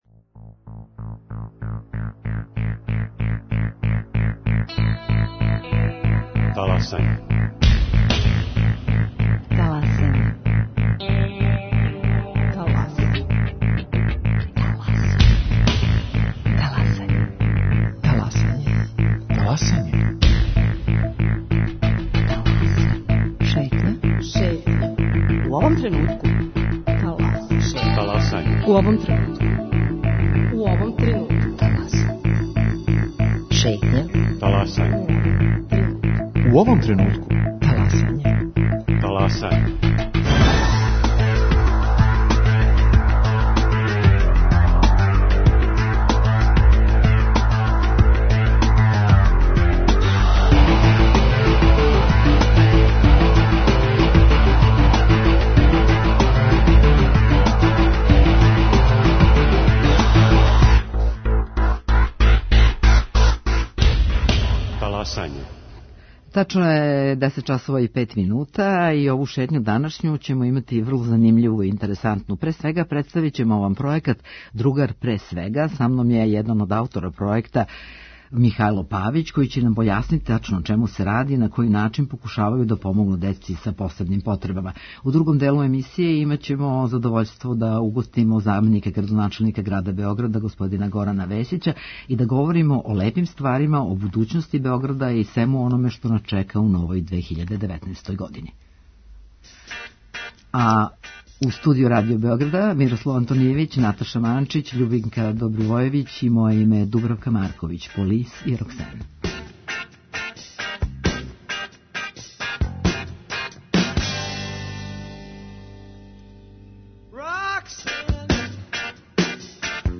У госте нам долази и заменик градоначелника Београда Горан Весић. Упознаће нас са плановима града у наредној години и представити манифестације које град припрема за предстојеће празнике.